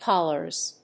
/ˈkɑlɝz(米国英語), ˈkɑ:lɜ:z(英国英語)/